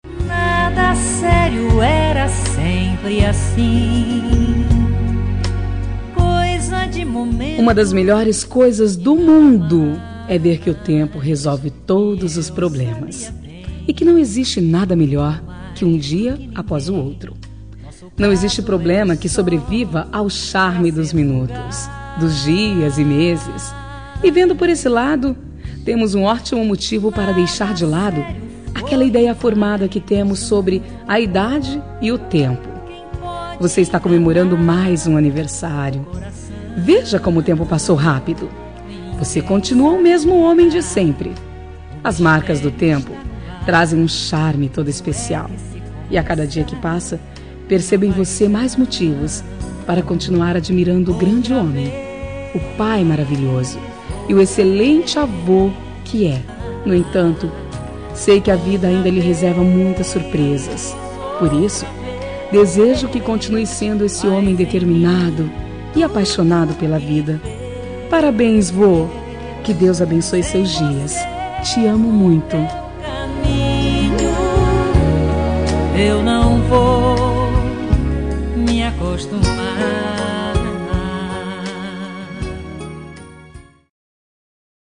Aniversário de Avô – Voz Feminina – Cód: 2087